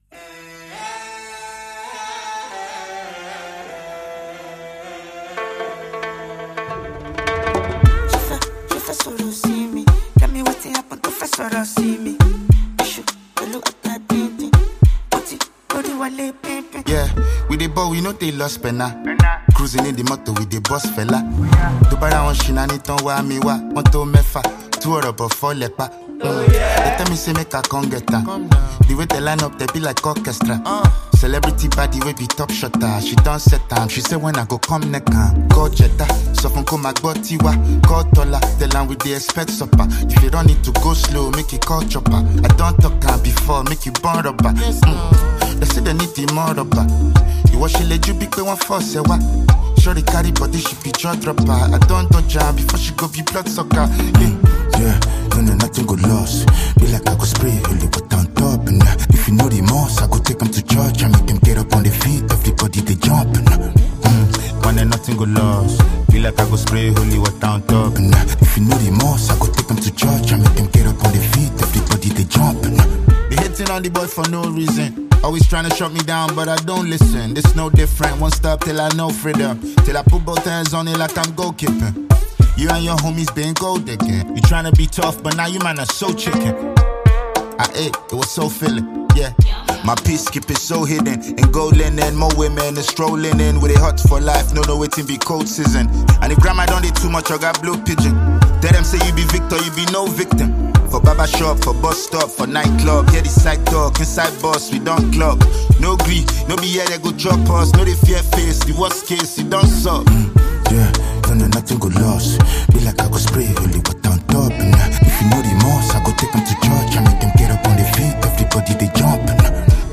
Nigerian rapper and singer
offering a mix of Afrobeat, hip-hop, and highlife sounds.